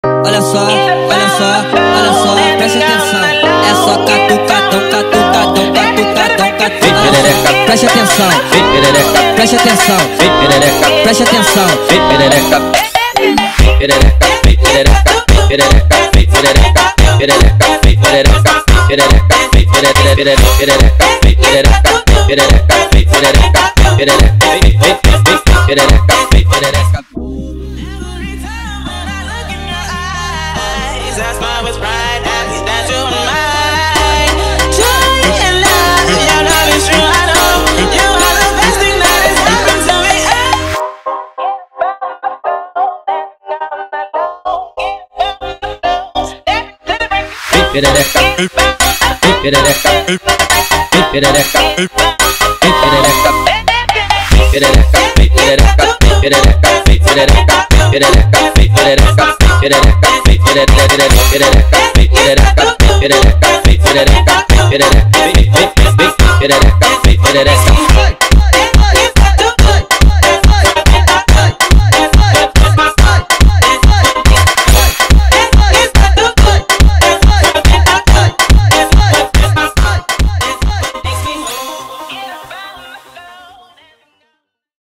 با ریتمی سریع شده
فانک